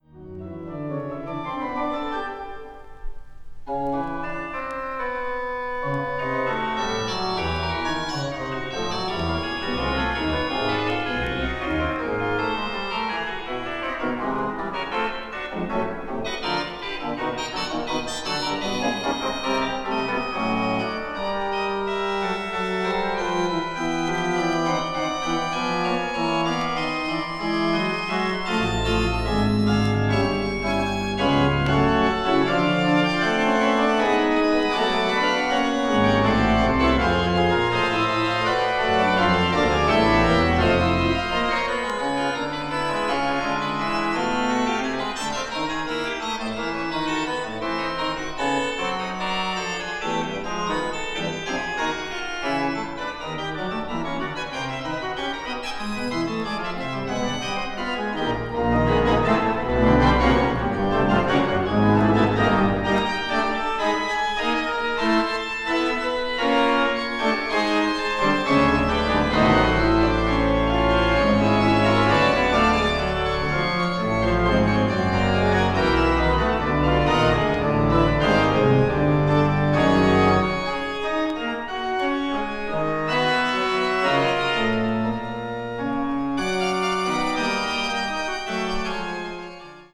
media : EX/EX(わずかにチリノイズが入る箇所あり)
20th century   contemporary   orchestra   organ   serialism